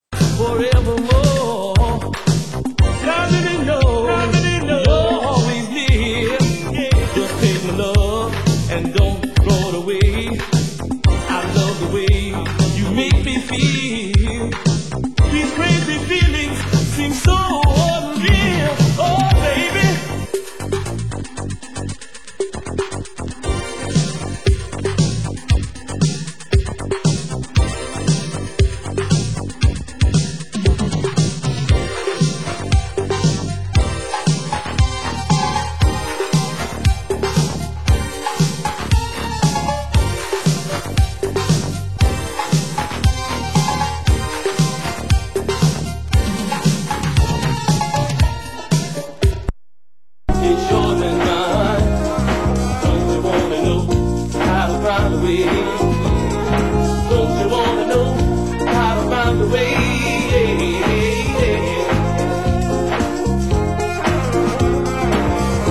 Genre: Electro